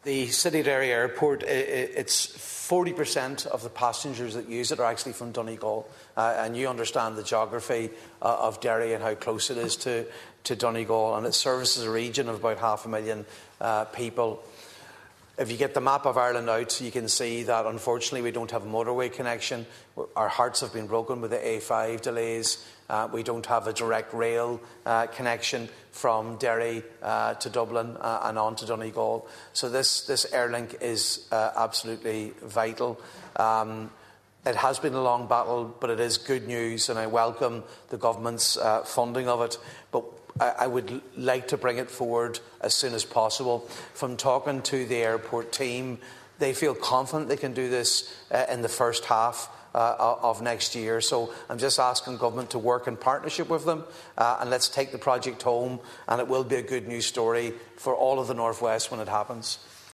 Deputy Padraig Mac Lochlainn was speaking in the Dáil last night, highlighting its importance to Donegal.